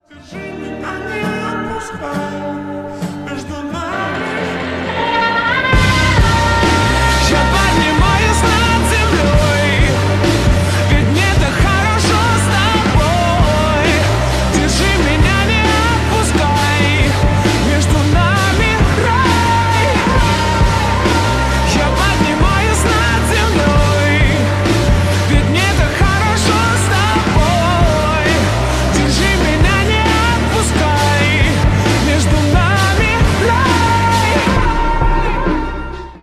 бесплатный рингтон в виде самого яркого фрагмента из песни